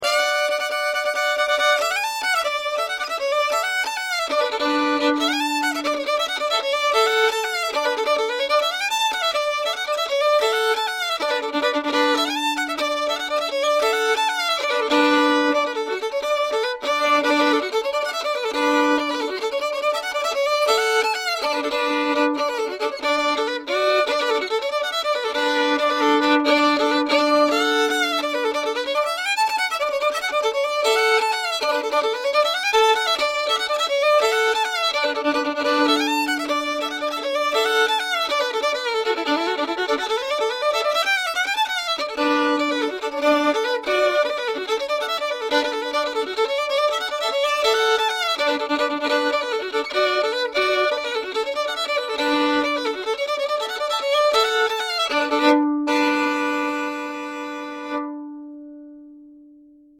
v D Dur